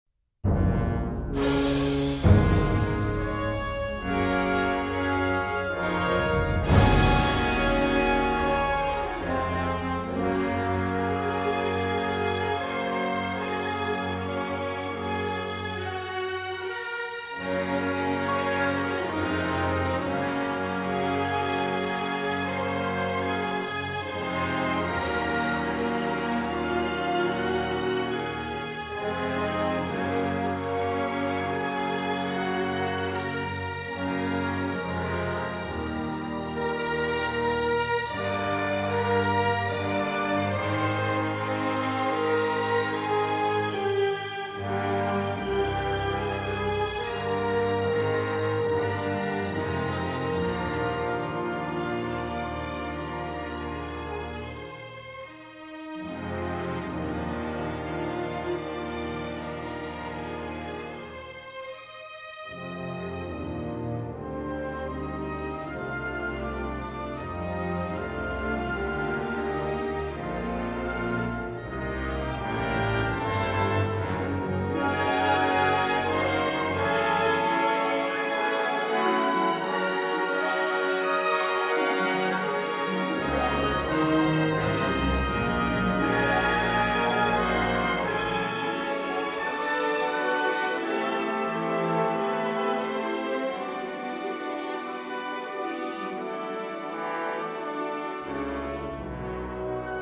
evocadora partitura